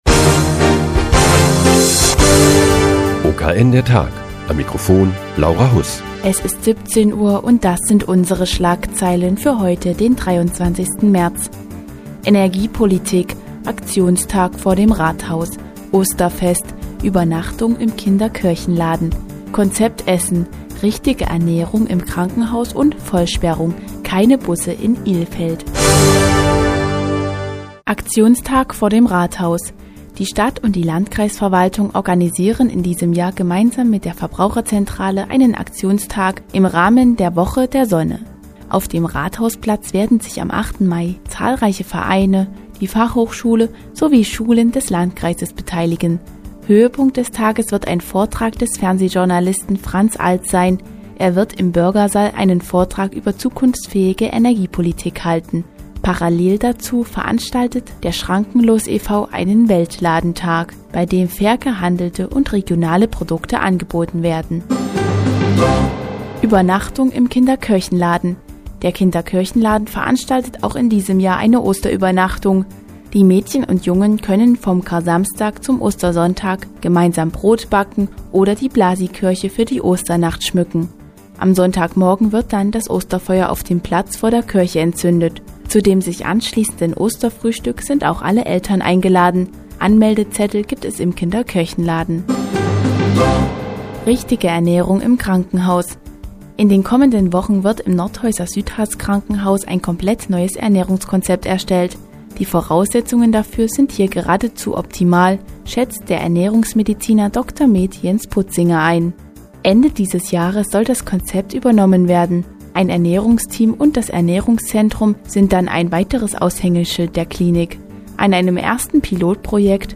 Die tägliche Nachrichtensendung des OKN ist nun auch in der nnz zu hören. Heute geht es um einen Aktionstag im Rahmen der "Woche der Sonne" und das neue Ernährungskonzept des Nordhäuser Südharz-Krankenhauses.